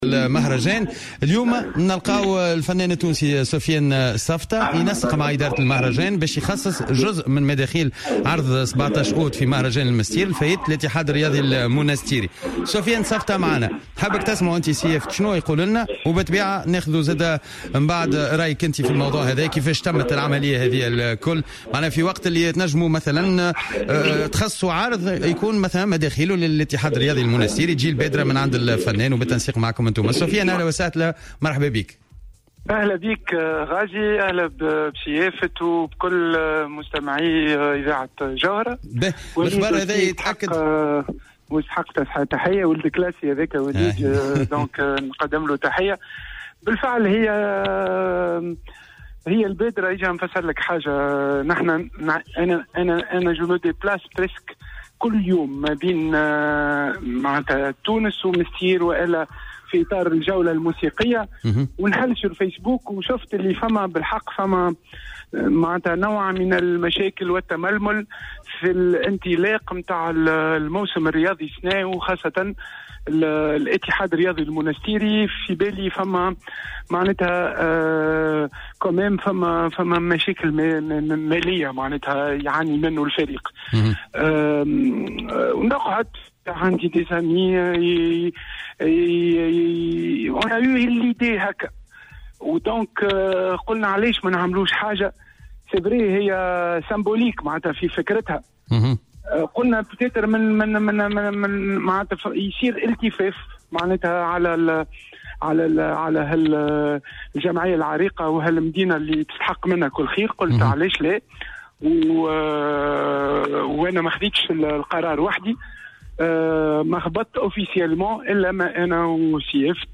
أكد الفنان سفيان سفطة في مداخلة له على الجوهرة "اف ام" مساء اليوم الأحد 5 أوت 2018 أنه قرر بعد التنسيق مع إدارة مهرجان المنستير الدولي تخصيص جزء من مداخيل عرضه يوم 17 أوت الجاري بمهرجان المنستير الدولي لفائدة الإتحاد الرياضي المنستيري .